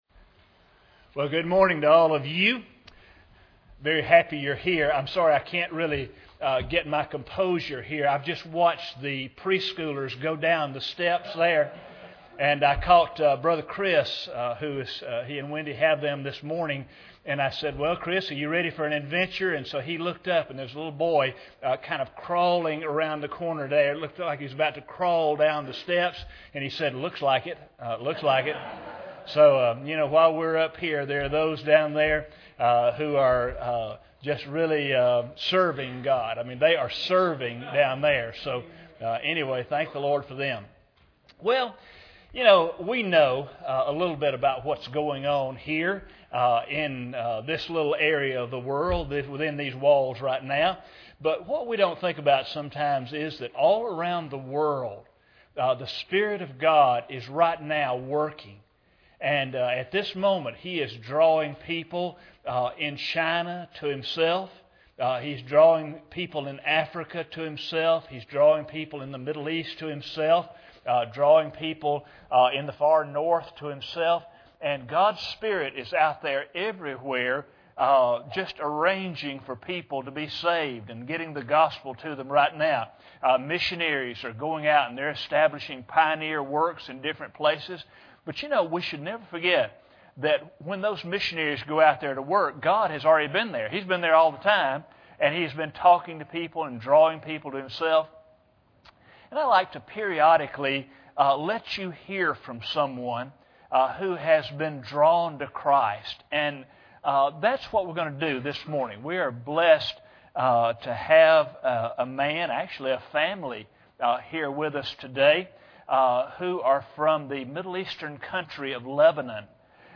General Service Type: Sunday Morning Preacher